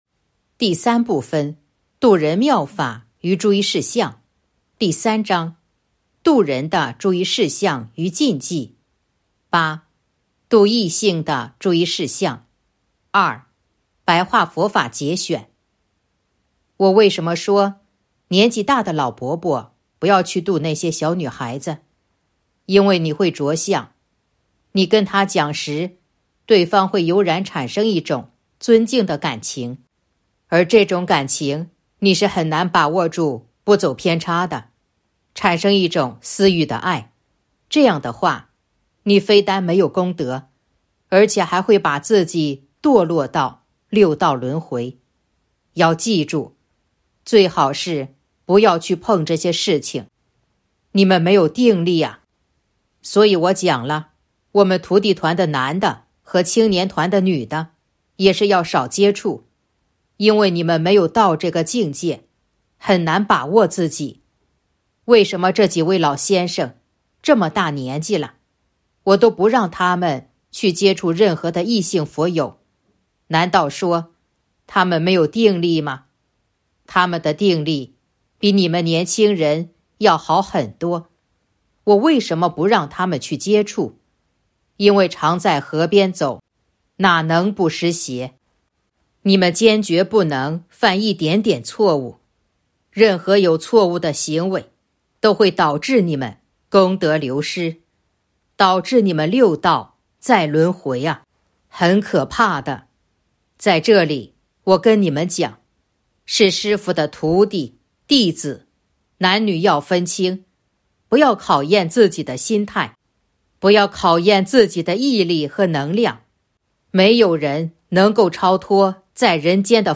063.2. 白话佛法节选《弘法度人手册》【有声书】